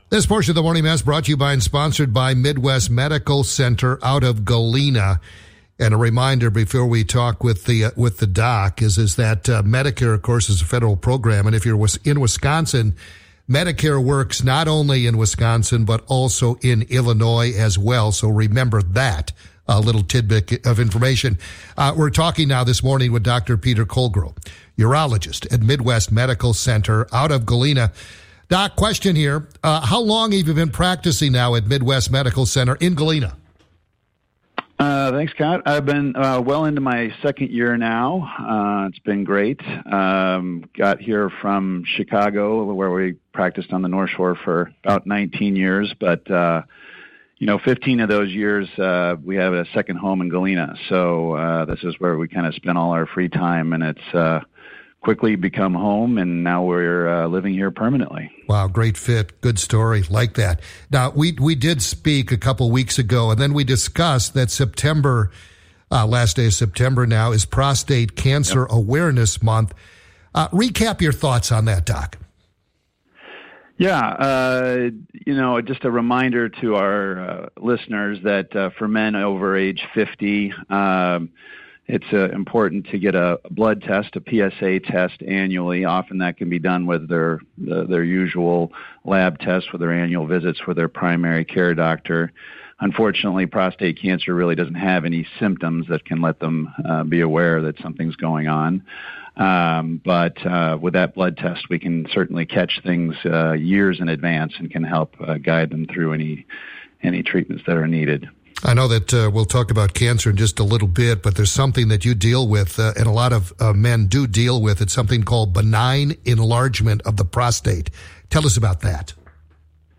Radio Interview | 9/30/25 Prostate Cancer Awareness Month & Benign Prostate Enlargement